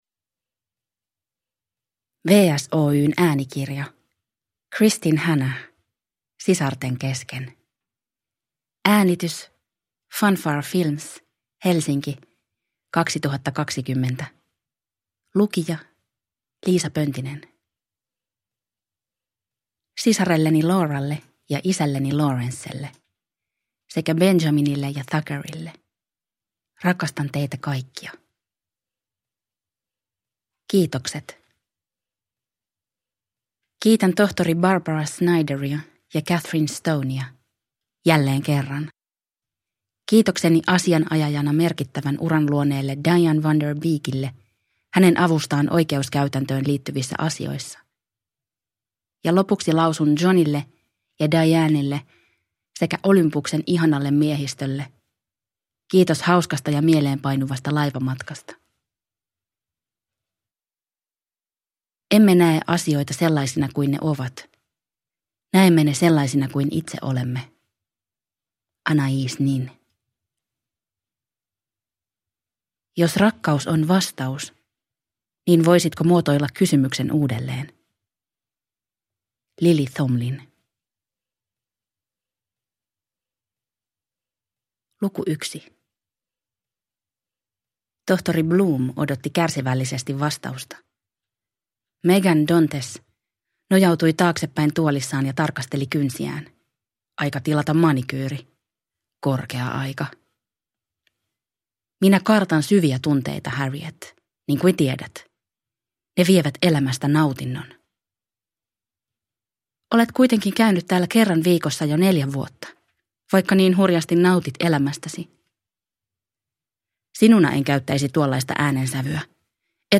Sisarten kesken – Ljudbok – Laddas ner